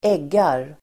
Uttal: [²'eg:ar]